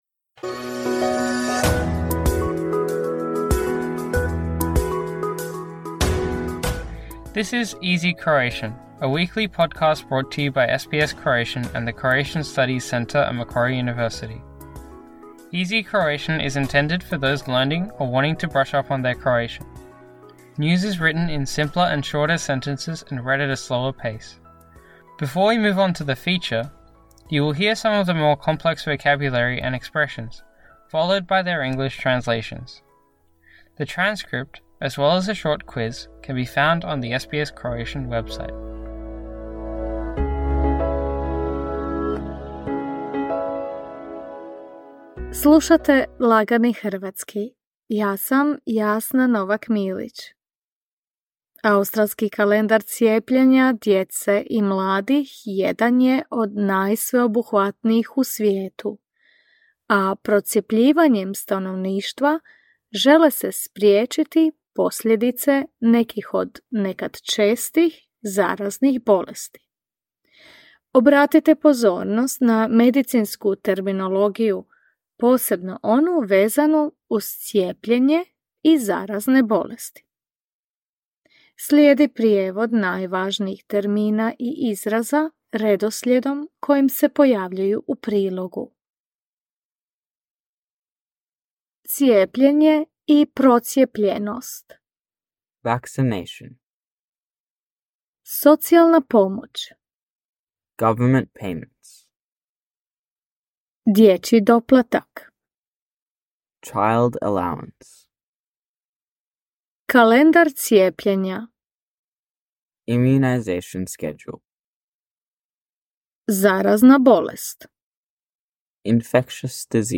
“Easy Croatian” is intended for those learning or wanting to brush up on their Croatian. News is written in simpler and shorter sentences and read at a slower pace. Before we move on to the feature, you will hear some of the more complex vocabulary and expressions, followed by their English translations.